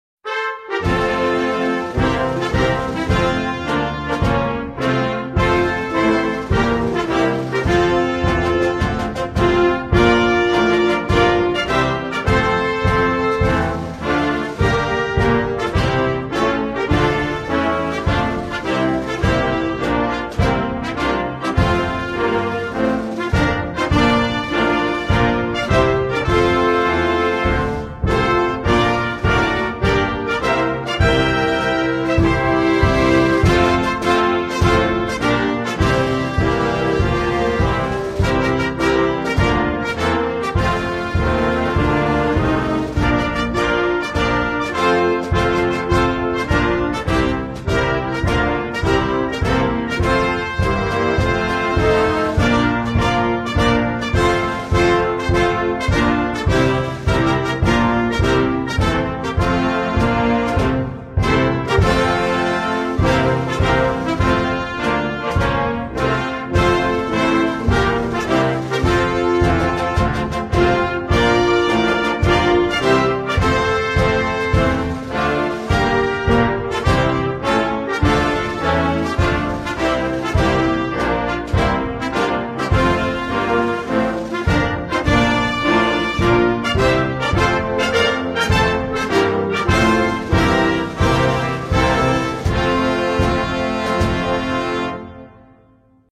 Comoros_National_Anthem_Instrumental.mp3